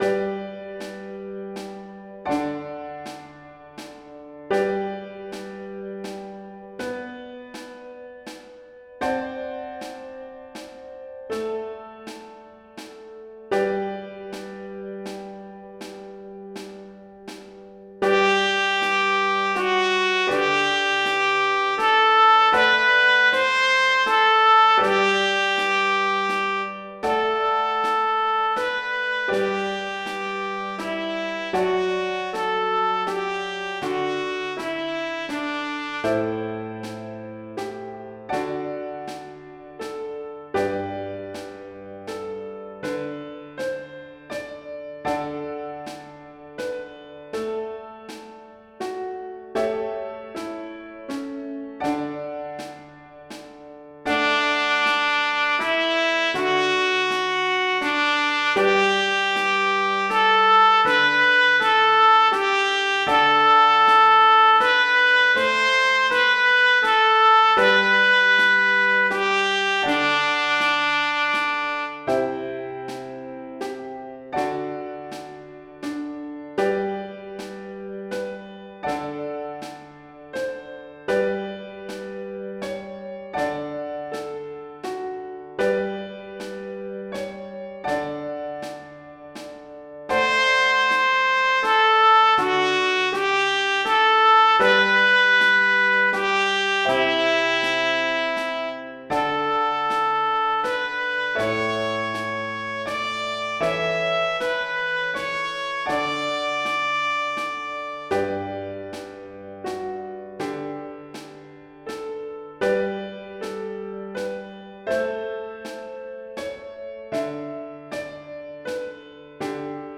Printed sheet music- #102 – Trumpet in Bb
Practice track at 80mm
6. When playing with the midi trumpet melody, out of tune notes will be obvious.
Note- If you have problems hearing the click track, just adjust your left and right fader for I recorded the midi trumpet and piano in one channel and the click track in the other.